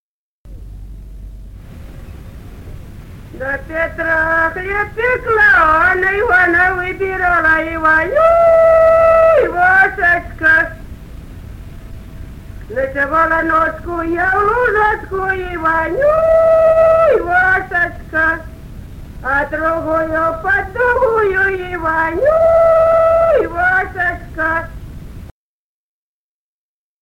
Народные песни Стародубского района «На Петра хлеб пекла», купальская.
с. Курковичи.